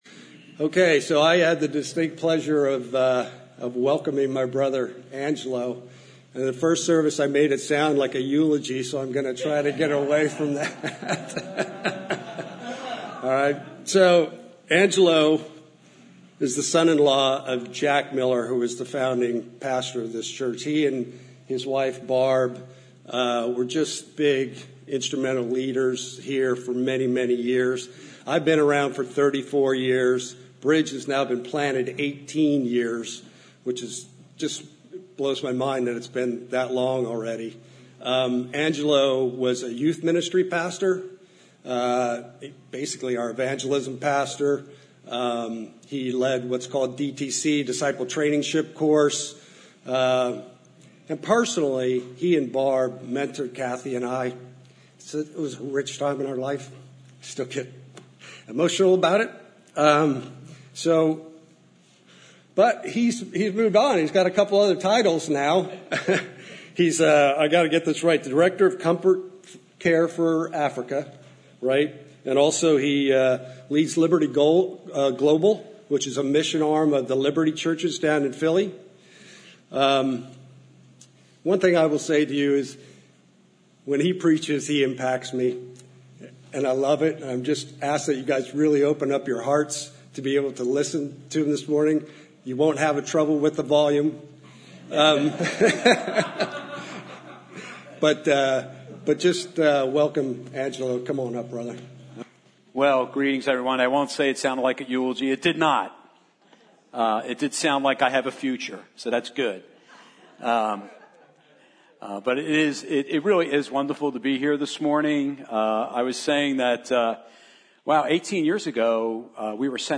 From Series: "50th Anniversary Sermons"